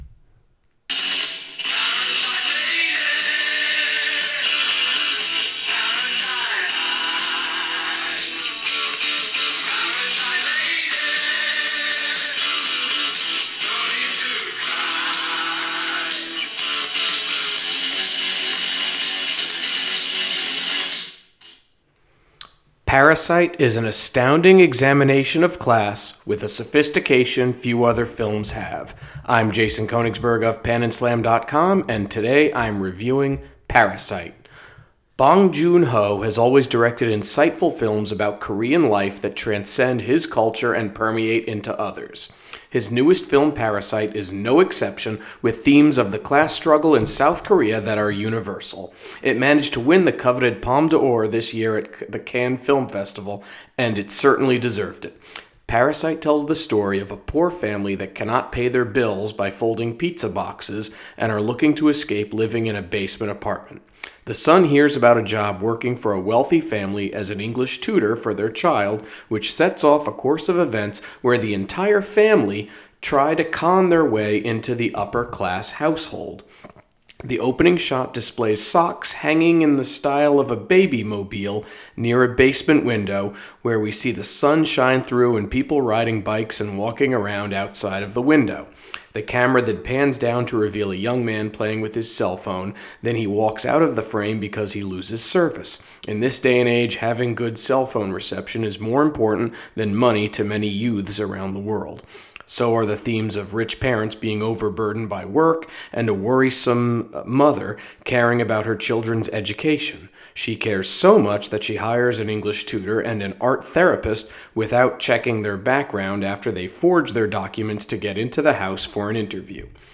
Movie Review: Parasite